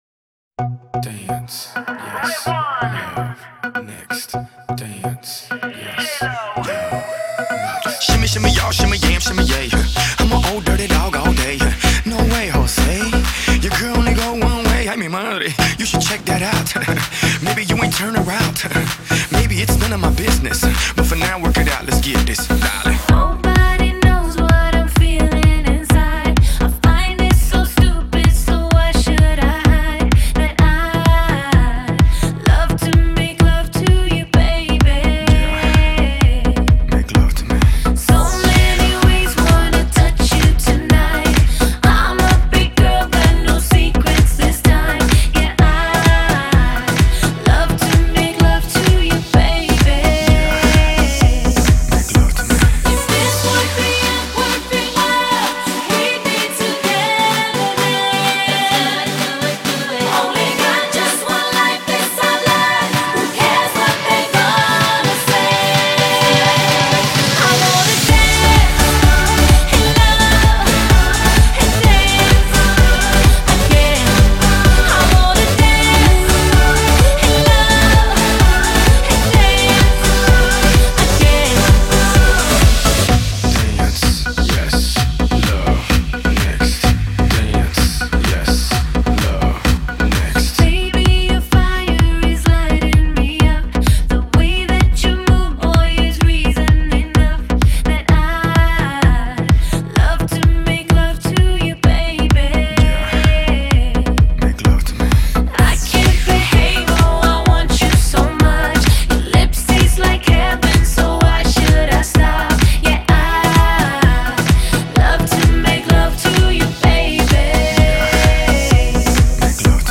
〽 ژانر Pop - UK R&B